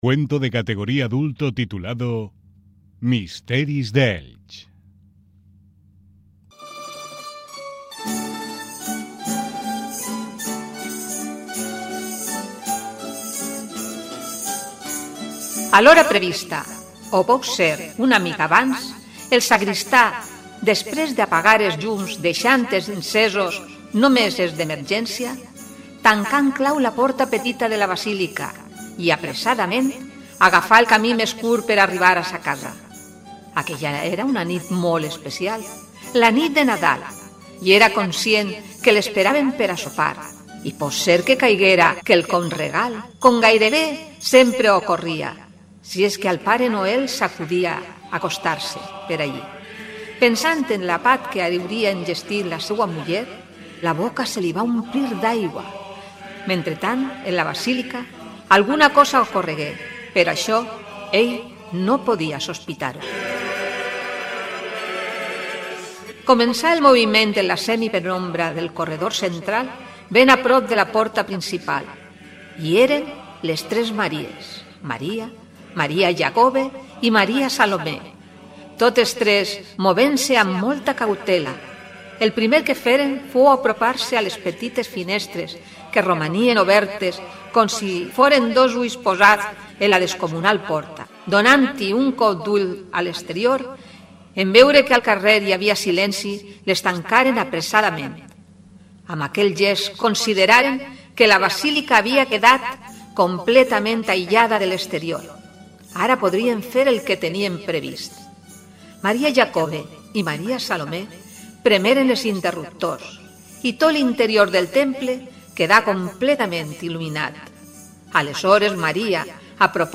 Cuentos radiofónicos Navideños